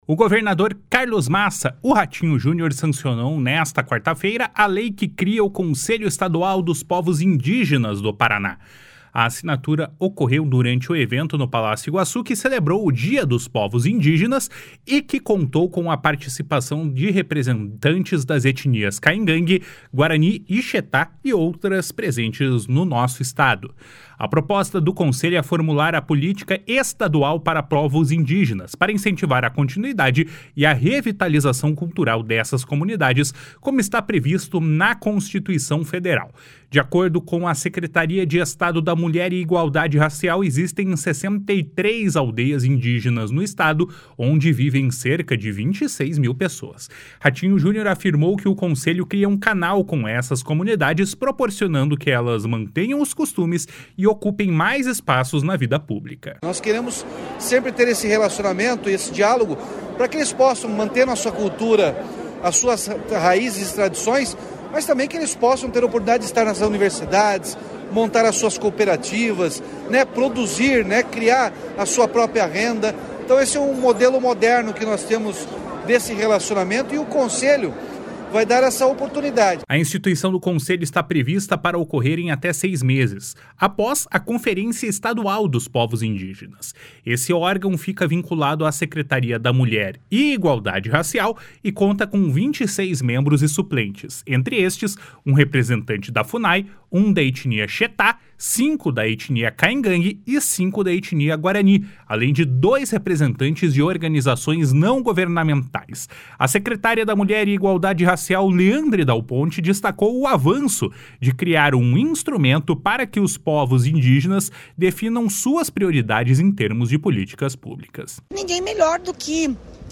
O governador Carlos Massa Ratinho Junior sancionou nesta quarta-feira a lei que cria o Conselho Estadual dos Povos Indígenas do Paraná. A assinatura ocorreu durante o evento, no Palácio Iguaçu, que celebrou o Dia dos Povos Indígenas e contou com a participação de representantes das etnias Caigangue, Guarani e Xetá e outras presentes no Estado.
// SONORA RATINHO JUNIOR //